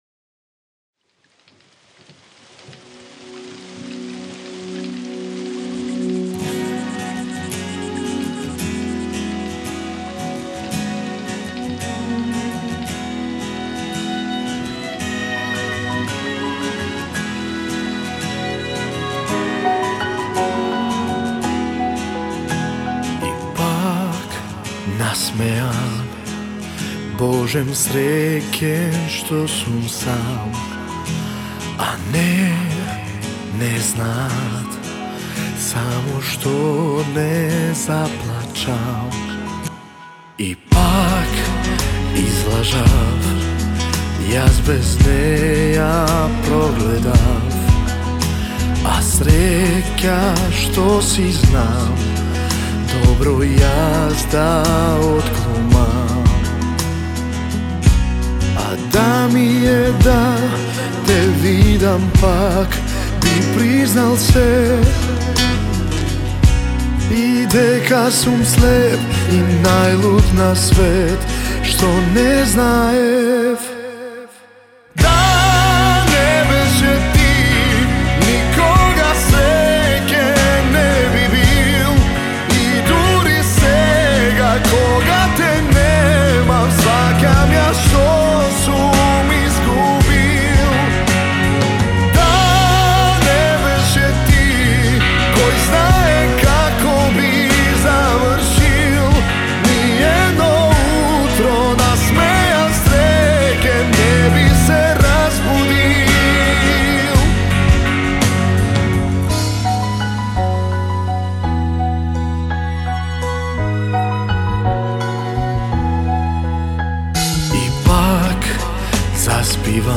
балада